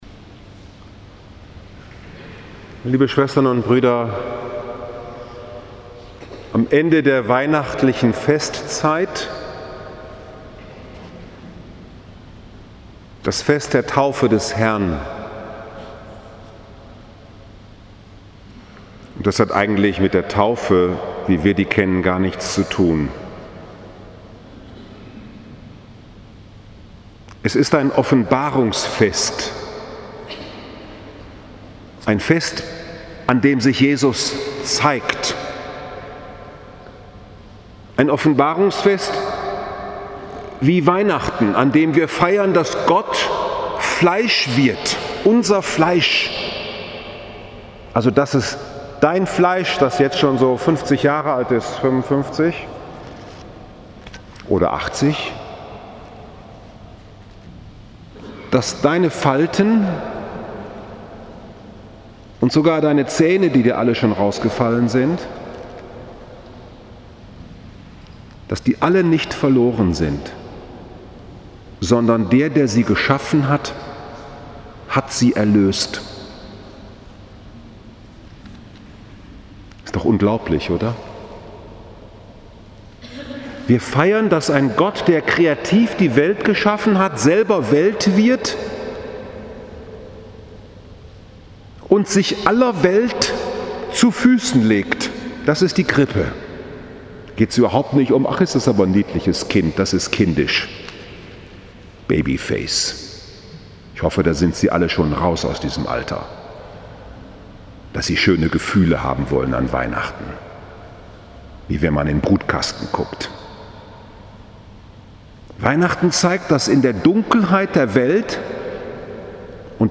Gottes Einsinken in seine Schöpfung Sich in den Fluß des göttlichen Wirkens mitreißen lassen 12. Januar 2019, 18 Uhr Frankfurter Dom Fest der Taufe des Herrn